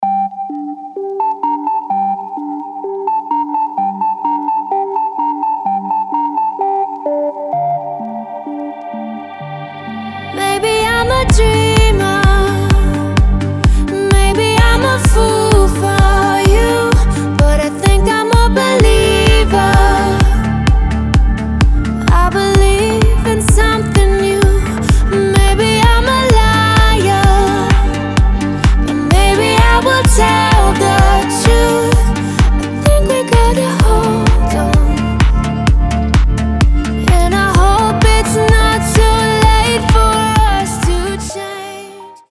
женский голос
Electronic
EDM
нарастающие
Midtempo
Cover
космические